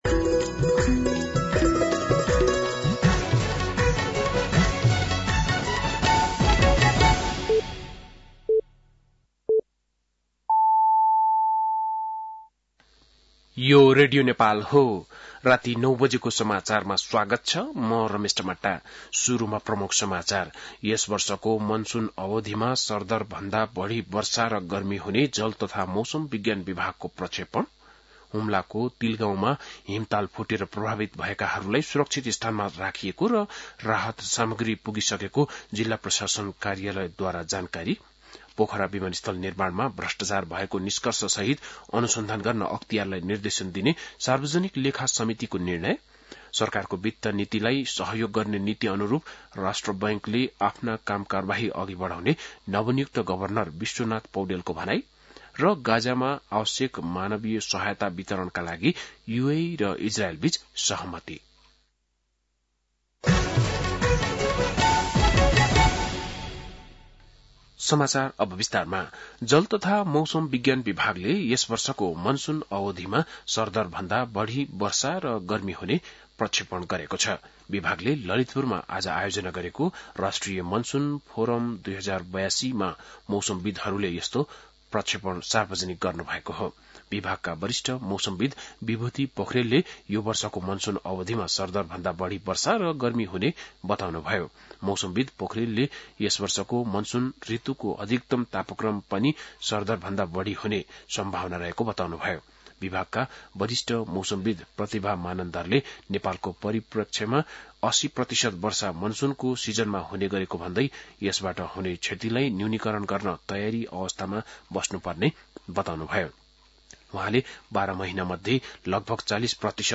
बेलुकी ९ बजेको नेपाली समाचार : ७ जेठ , २०८२